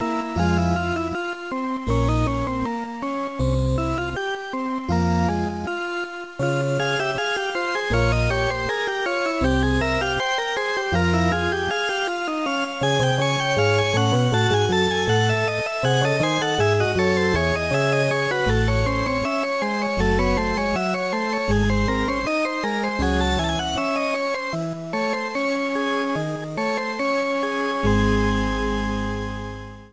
Clipped and applied fade-out with Audacity.